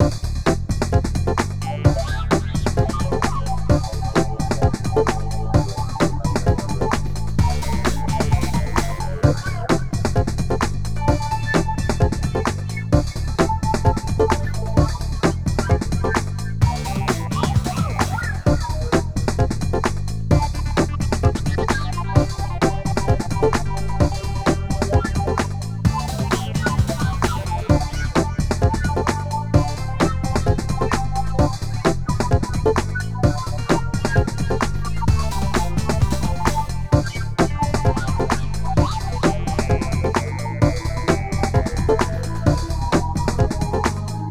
Tipo videojuego 2 (bucle)
videojuego
repetitivo
rítmico
sintetizador